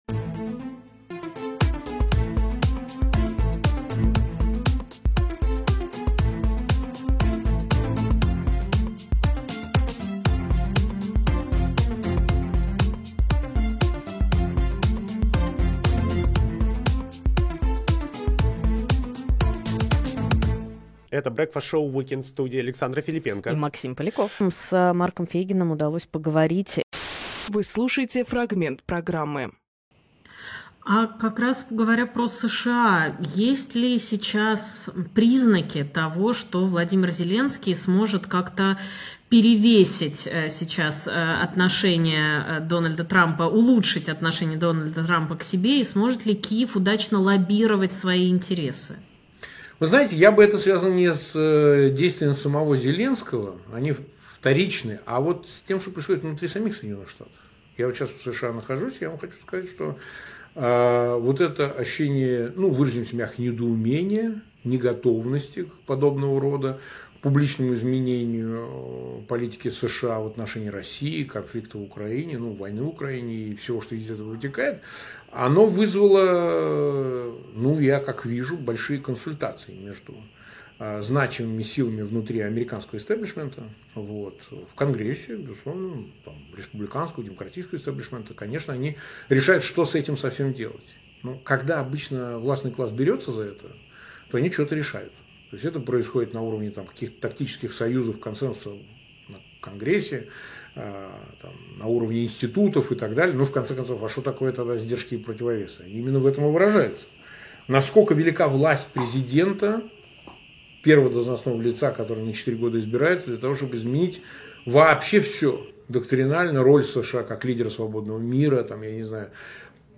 Фрагмент эфира от 23.02